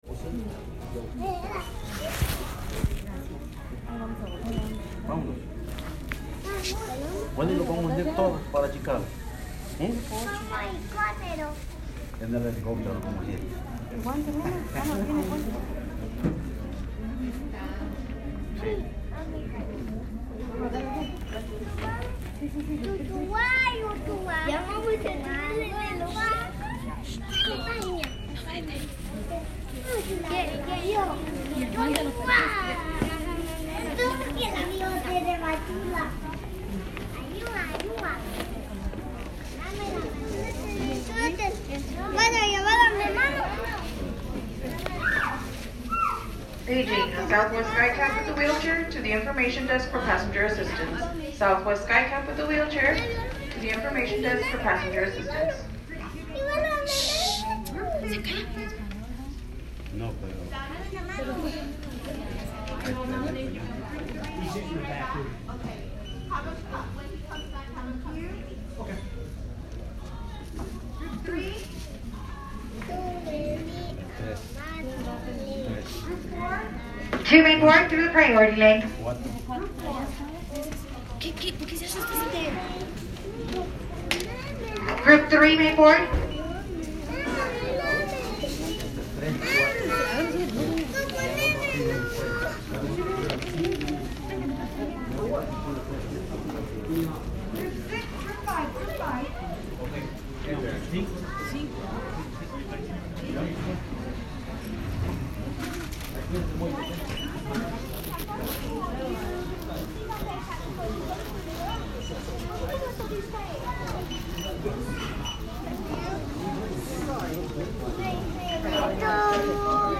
Small groups of migrants (a family with small children, and some young individuals) are at the board at El Paso airport getting ready to board a flight to Chicago.
Part of the Migration Sounds project, the world’s first collection of the sounds of human migration.